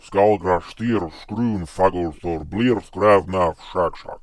It incorporates a range of vocalizations, alternating between normal voice production and deep, guttural growls, reminiscent of the Fomorians' intimidating presence.